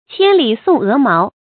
成語注音 ㄑㄧㄢ ㄌㄧˇ ㄙㄨㄙˋ ㄜˊ ㄇㄠˊ
成語拼音 qiān lǐ sòng é máo
千里送鵝毛發音